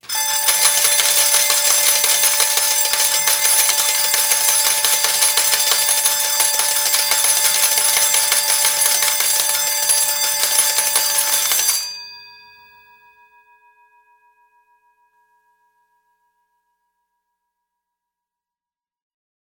Звуки игрового автомата
Звук победы джекпота в игровом автомате казино